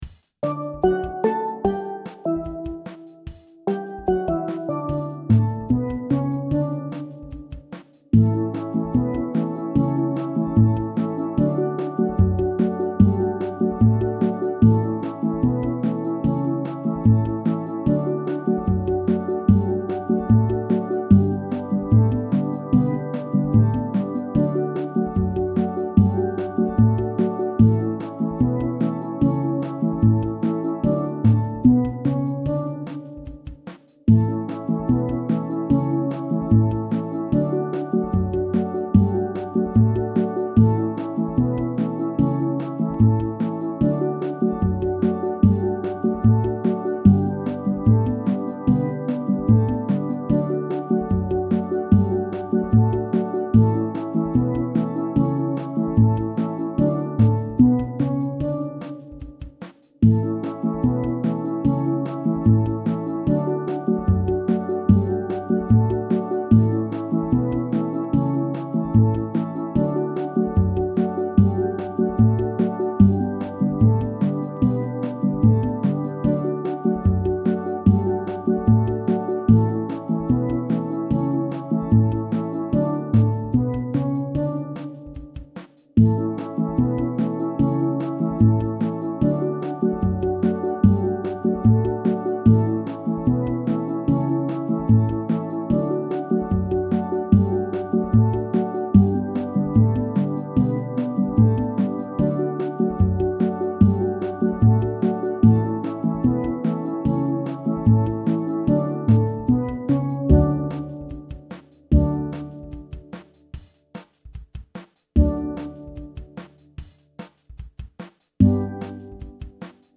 Steel Band Sound Files
These mp3's are up to tempo.